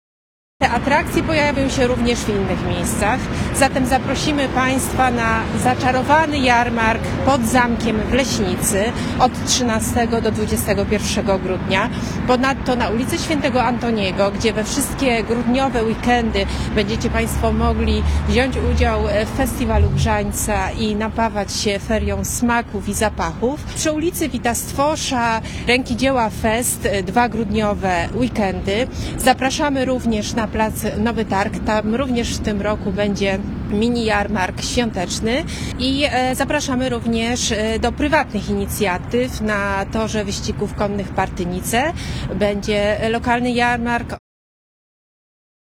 O szczegółach tego rozwiązania i prognozowanej frekwencji mówi Radiu LUZ